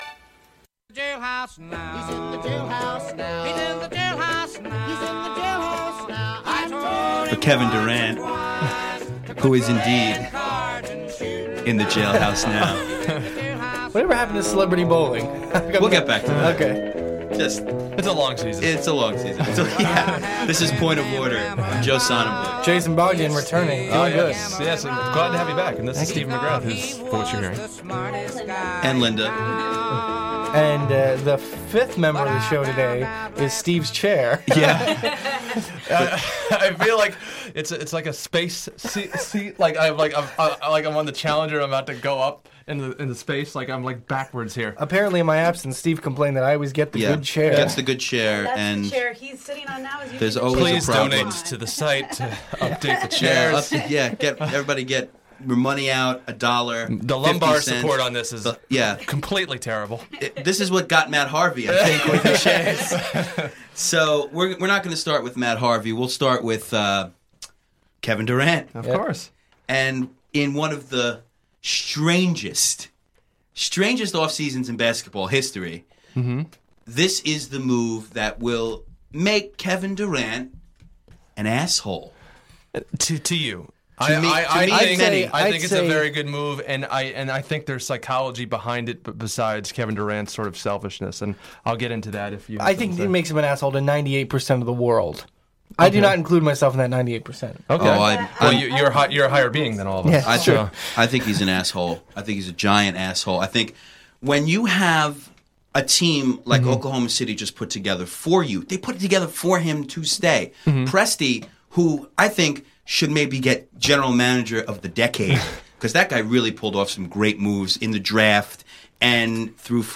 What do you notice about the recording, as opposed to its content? Phone calls abounds on this episode. The group talks on Kevin Durant and his future with the Warriors.